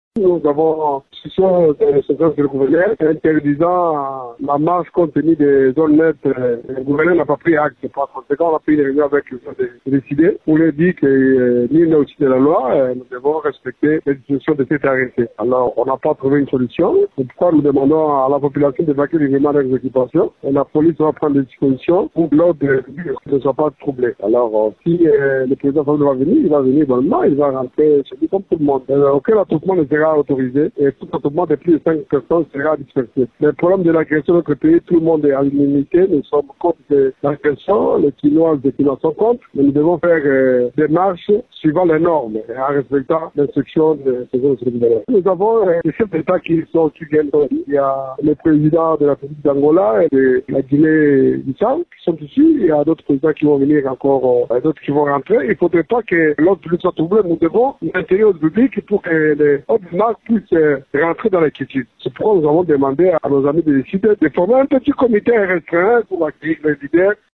Dans une interview à Radio Okapi, le commissaire provincial de la police, Sylvano Kasongo, indique que, d'après les instructions de l'autorité urbaine de Kinshasa, cette initiative de l'ECIDE est interdite.